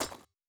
Grenade Sound FX
Impact on Dirt.wav